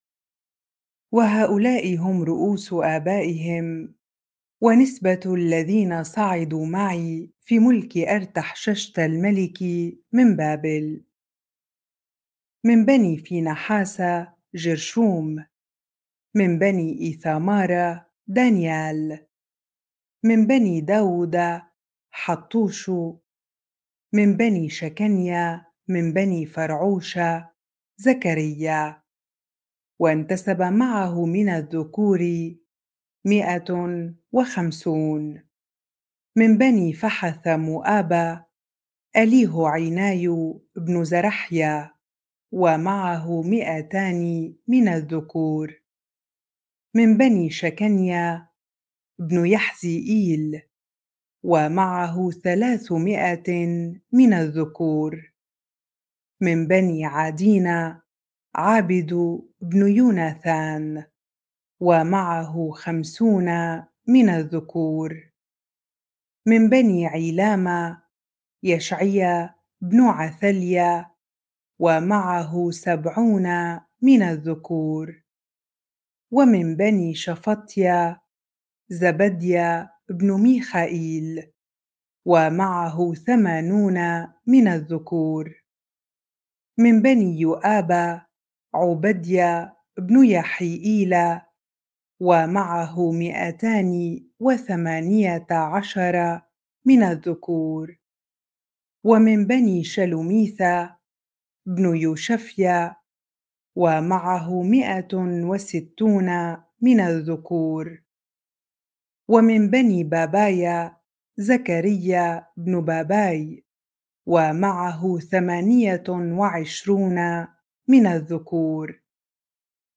bible-reading-Ezra 8 ar